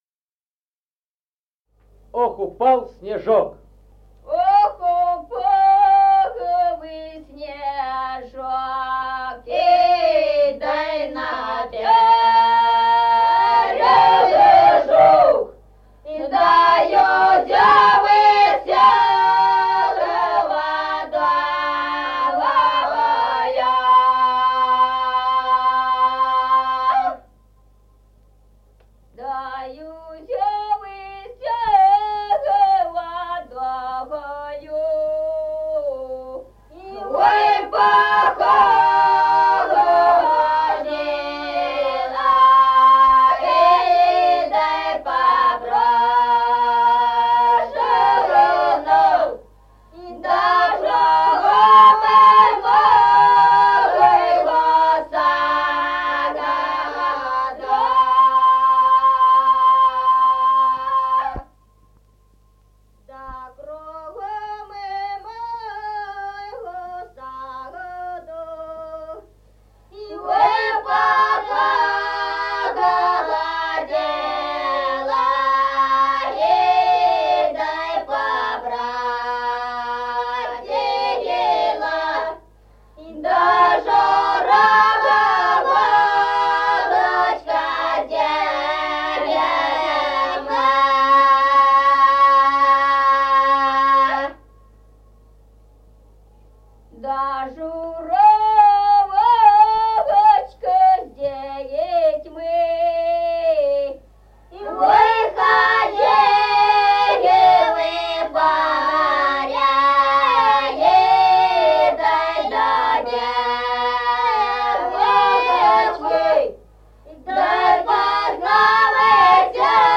Музыкальный фольклор села Мишковка «Ох, упал снежок», лирическая.